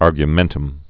(ärgyə-mĕntəm)